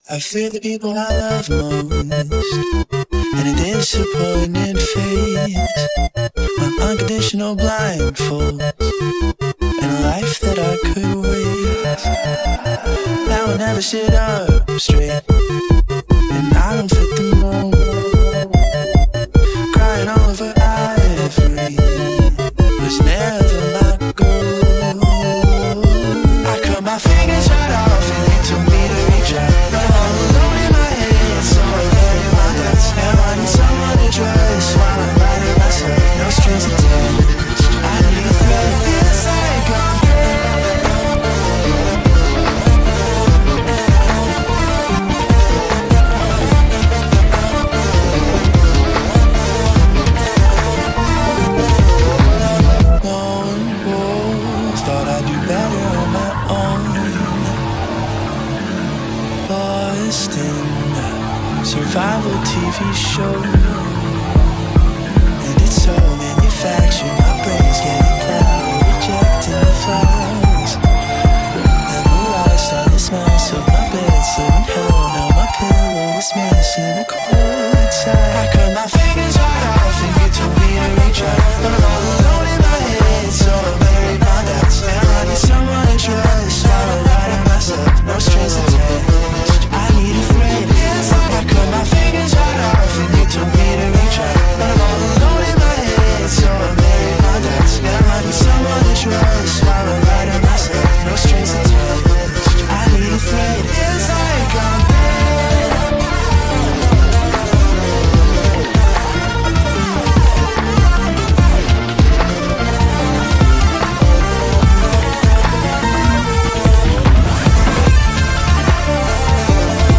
And the beat to this is so wicked.